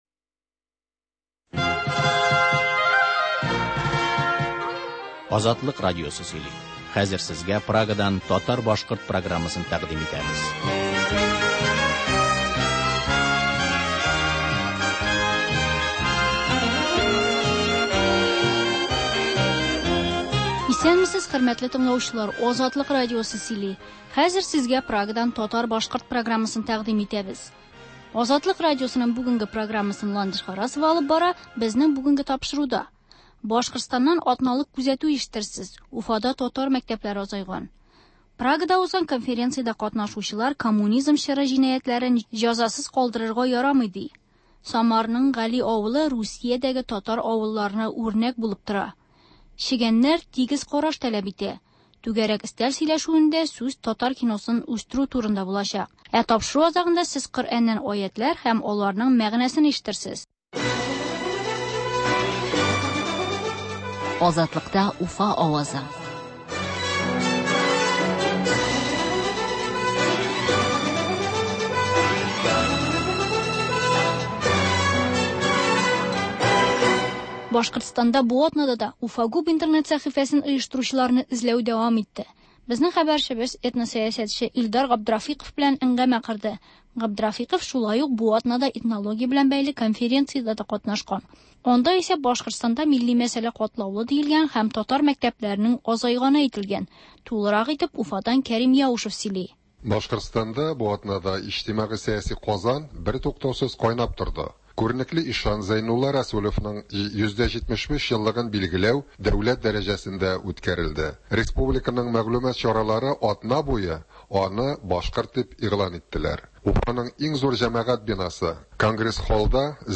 сәгать тулы хәбәр - Башкортстаннан атналык күзәтү - түгәрәк өстәл артында сөйләшү